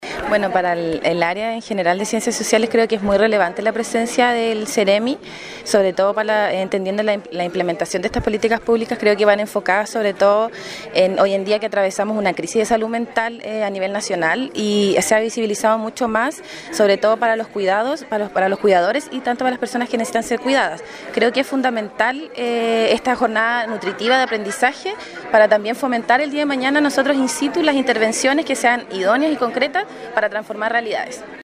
En el Instituto Profesional Santo Tomás Osorno se llevó a cabo ayer el conversatorio titulado “Chile Cuida: Construyendo un País que Protege”, en el marco de la Semana de Servicio Social. Esta significativa actividad tiene como objetivo promover acciones de corresponsabilidad social en el cuidado, contribuyendo así al desarrollo del país.